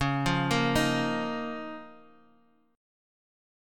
C#6add9 chord